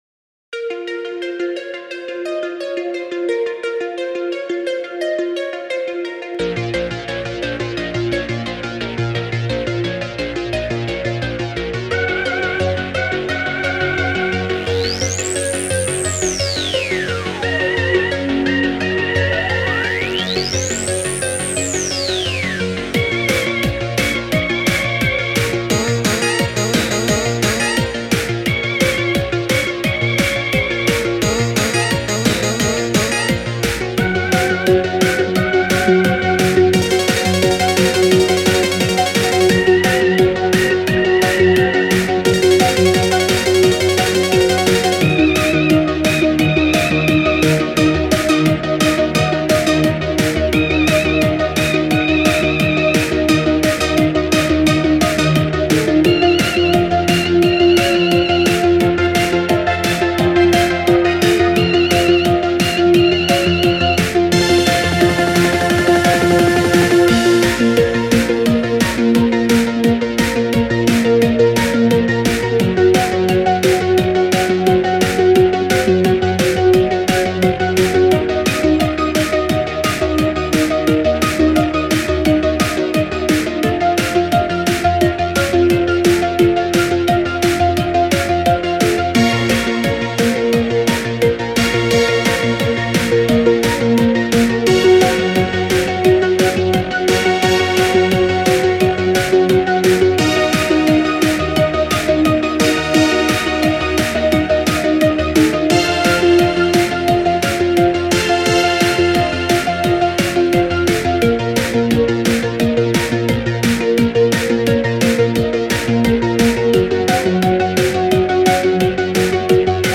Spacesynth Trance Techno Relax
Meditative Newage Space